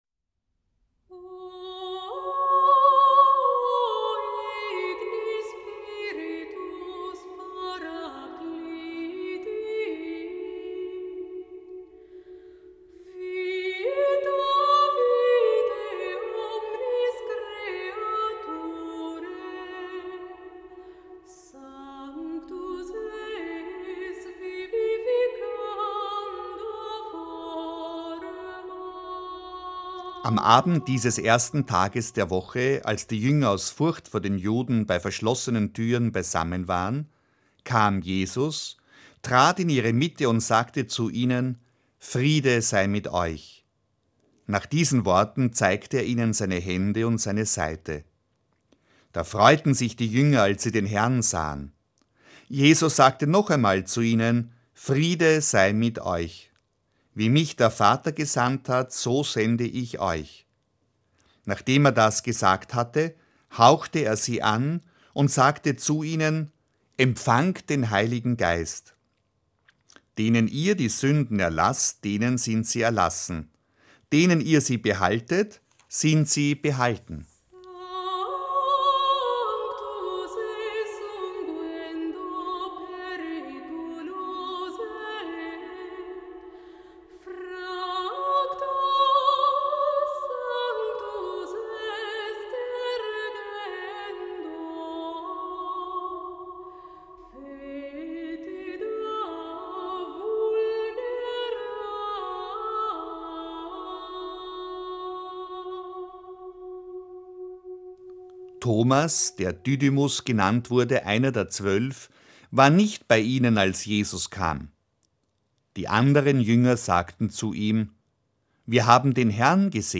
Für alle, die nicht mitkommen können/wollen, bieten wir hier eine kurze Emmausandacht zum Anhören an, gestaltet von Radio Klassik, Christoph Kardinal Schönborn und Dompfarrer Toni Faber.